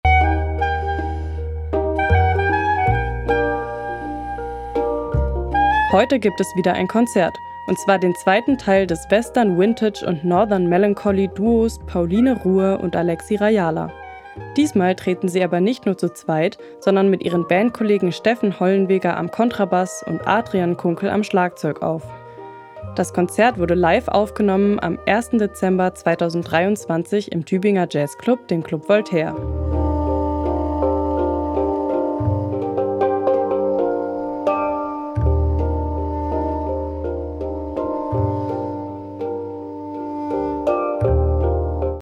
Das Jazzduo mit Band
Gesang
Gitarre
Kontrabass
Schlagzeug